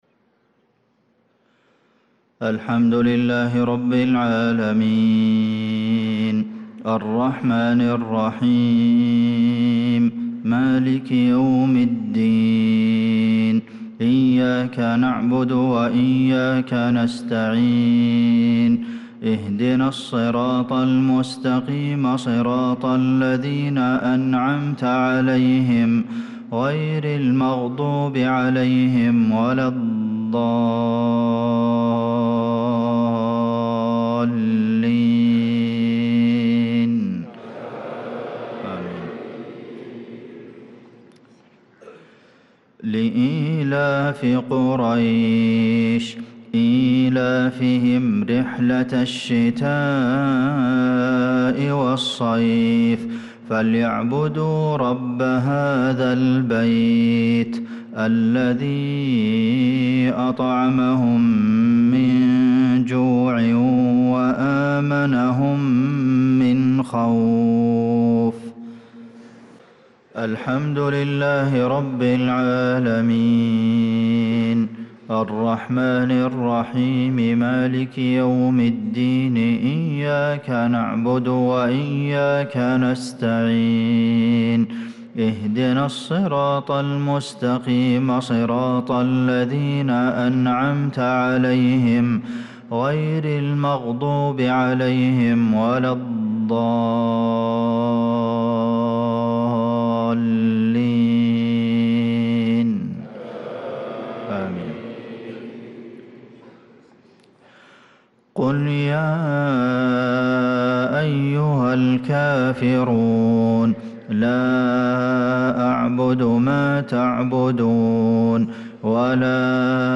صلاة المغرب للقارئ عبدالمحسن القاسم 4 شوال 1445 هـ
تِلَاوَات الْحَرَمَيْن .